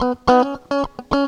GTR 96 C#M.wav